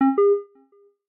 ChargingStarted_Retro.ogg